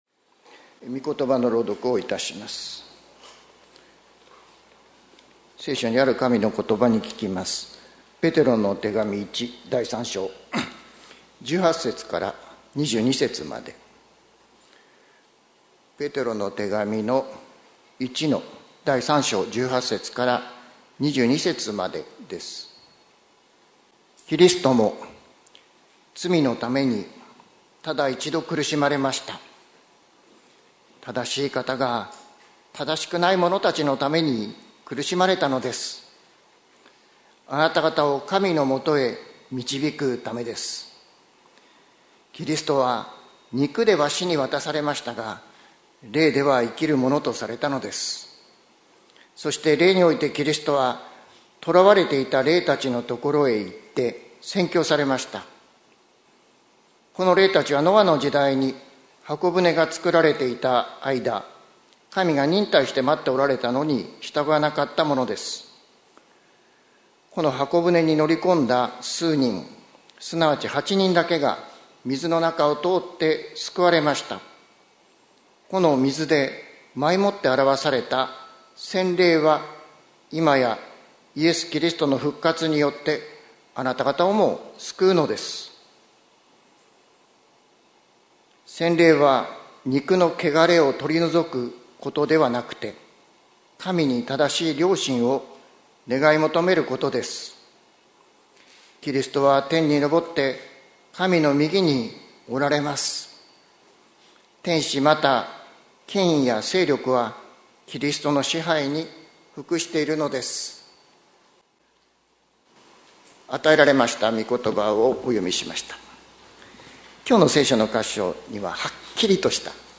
2025年01月12日朝の礼拝「どこまでも届く恵み」関キリスト教会
説教アーカイブ。